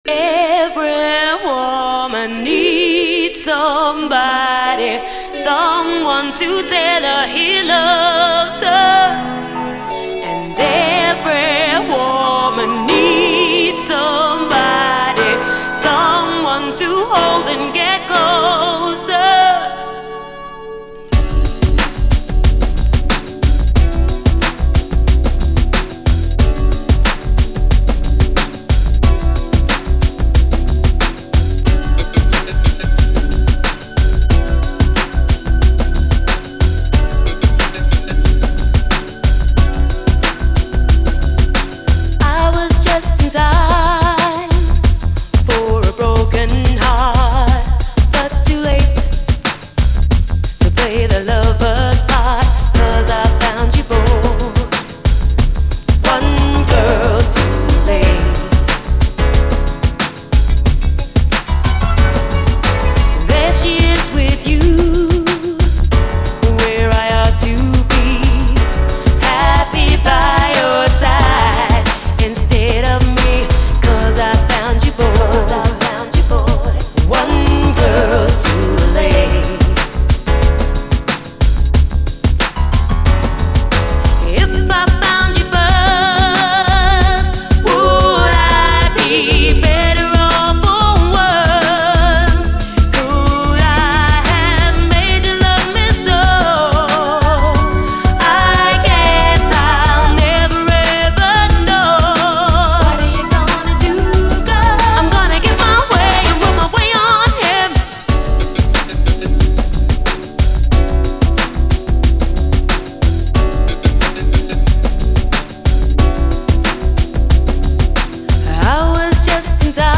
SOUL, 70's～ SOUL, REGGAE
真っ黒く深い最高UKストリート・ソウル＆UKラヴァーズ！
UKラヴァーズ・ヴァージョンも勿論素晴らしい！両面、インストも収録。